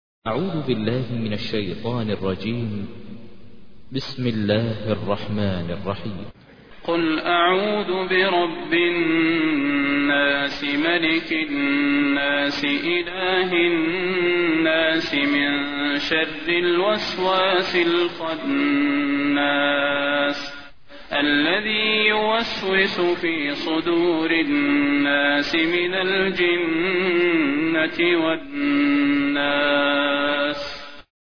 تحميل : 114. سورة الناس / القارئ ماهر المعيقلي / القرآن الكريم / موقع يا حسين